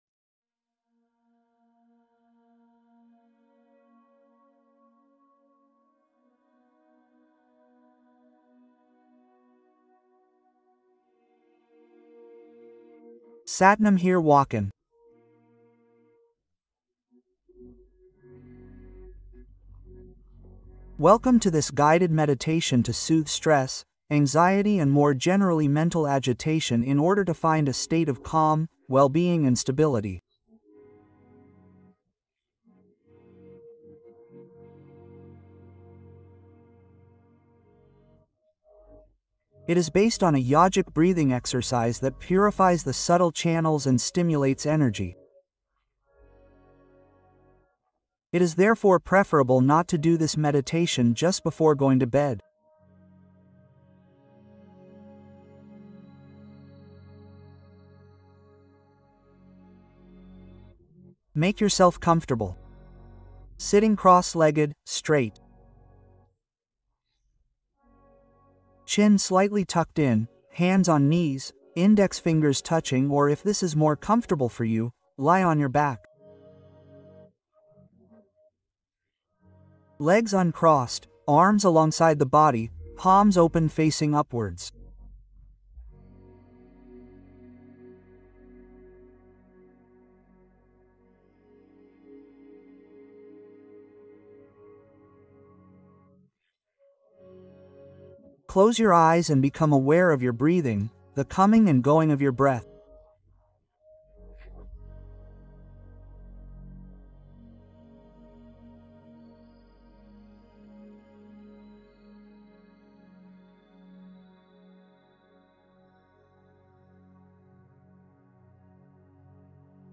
Relaxation Guidée